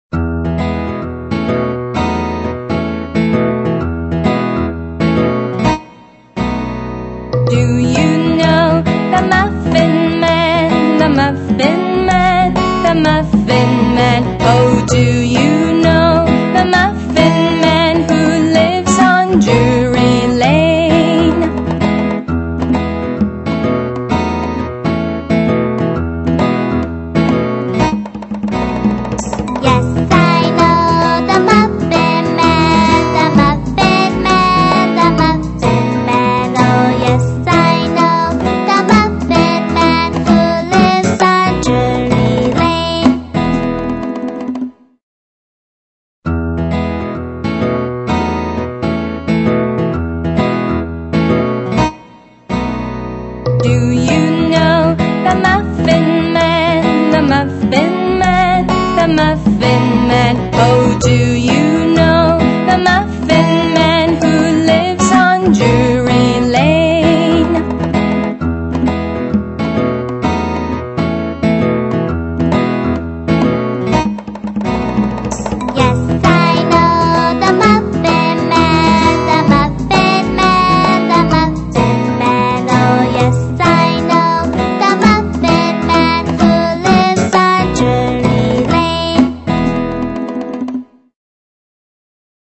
在线英语听力室英语儿歌274首 第33期:Do you know the Muffin Man的听力文件下载,收录了274首发音地道纯正，音乐节奏活泼动人的英文儿歌，从小培养对英语的爱好，为以后萌娃学习更多的英语知识，打下坚实的基础。